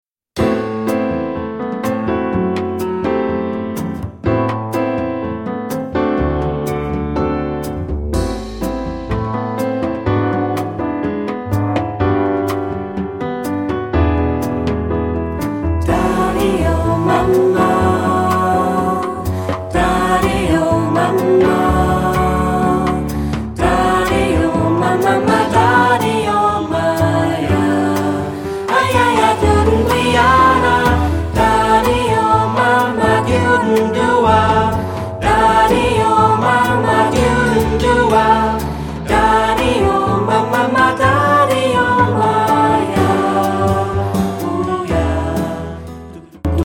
Kanon ; Choraljazz
Tonart(en): a-moll